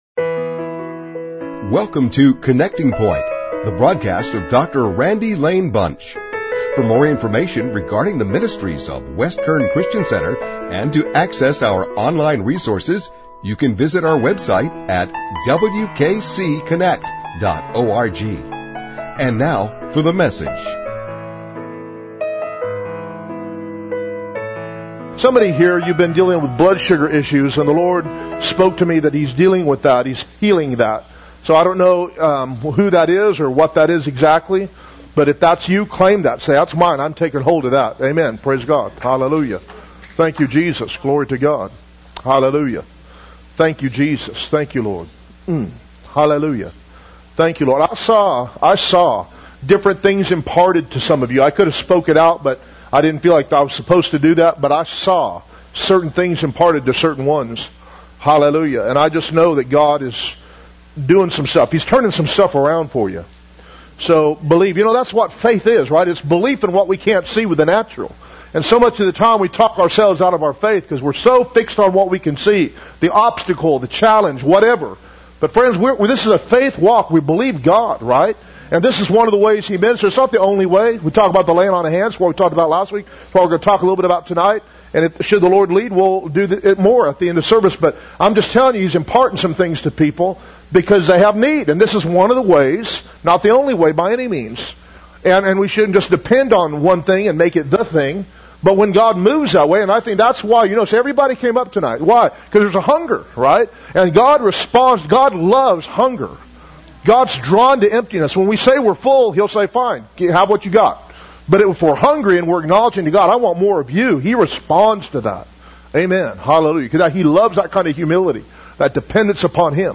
Holy Spirit Service
holy-spirit-service.mp3